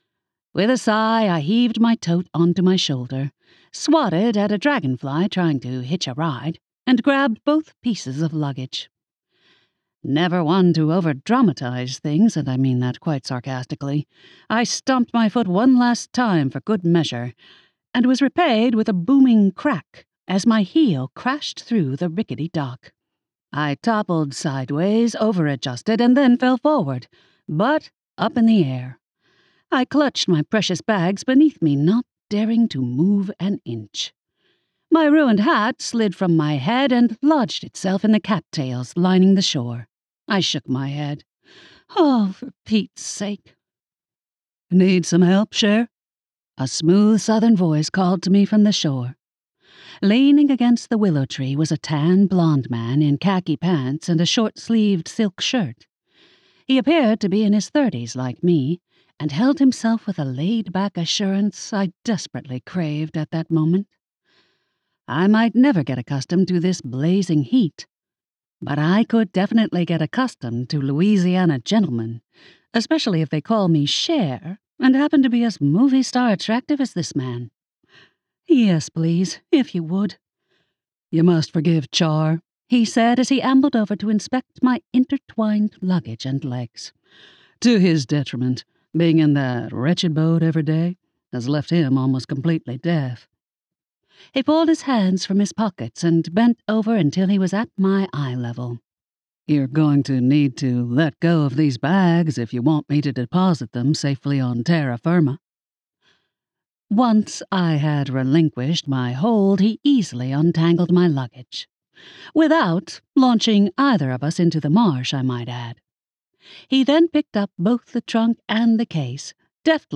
• Audiobook
Tolling-Bells---Dark-Spells-Audio-Sample.mp3